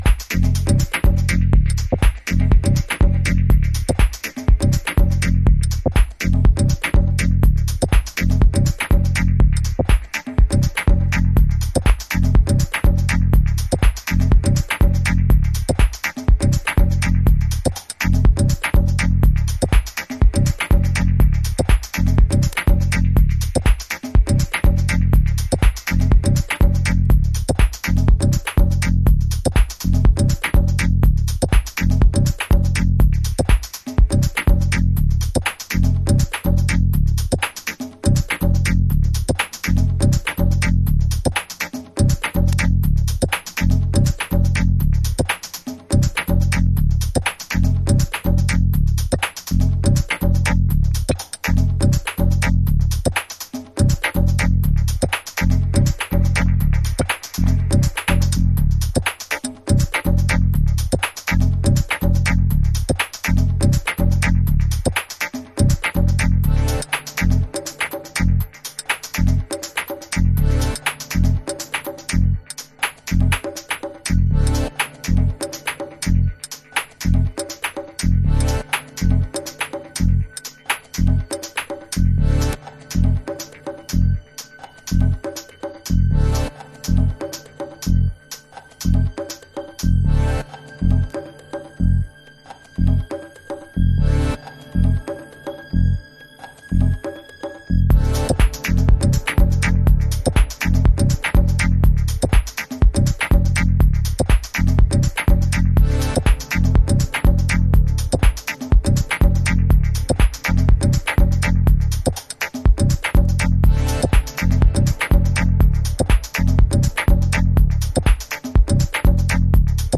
House / Techno
この方の繊細音響が生む、吸着力というか、独創的なグルーヴは中毒性高いです。